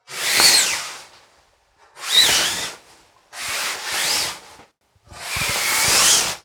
household
Cloth Slides